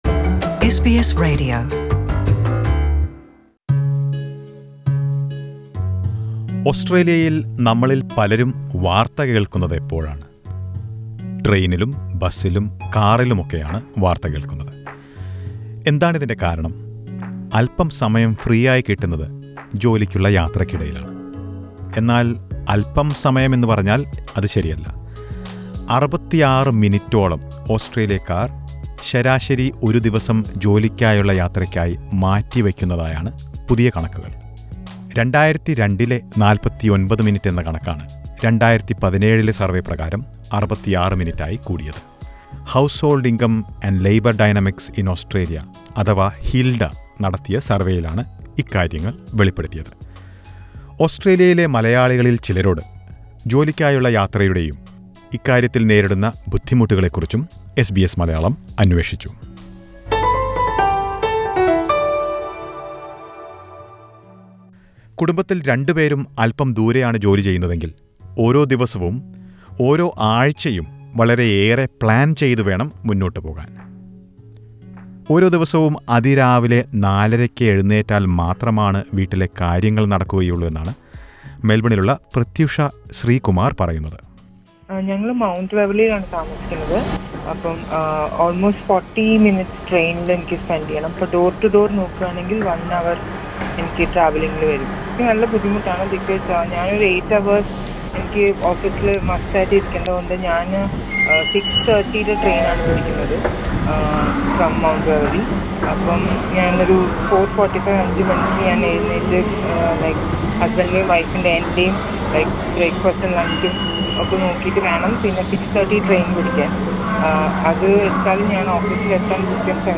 ഓസ്‌ട്രേലിയൻ നഗരങ്ങളിൽ താമസിക്കുന്നവർ ഓരോ ആഴ്ചയും മണികൂറുകളോളം ജോലിക്കായി യാത്രചെയ്യുന്നതായി അടുത്തിടെ പുറത്തുവന്ന പഠനം വെളിപ്പെടുത്തുന്നു. ജോലിക്കായുള്ള യാത്രാസമയം കൂടിയിരിക്കുന്നത് കുടുംബജീവിതത്തെ ബാധിക്കുന്നു എന്നതിനൊപ്പം ജോലിയെയും പ്രതികൂലമായി ബാധിക്കാമെന്നാണ് വിലയിരുത്തൽ. ജോലിക്കായുള്ള യാത്ര എങ്ങനെ ജീവിതത്തെ ബാധിക്കുന്നു എന്ന് എസ് ബി എസ് മലയാളം ചില ഓസ്‌ട്രേലിയൻ മലയാളികളോട് ആരായുന്നു.